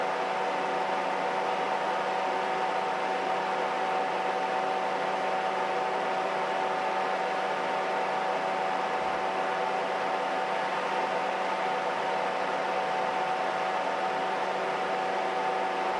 The dominant frequencies are in the 400Hz – 1.25KHz region.
I have recorded the signals shown above, but please keep in mind that I’ve enabled Automatic Gain Control (AGC) to do so to make it easier for you to reproduce them.
75% Fan Speed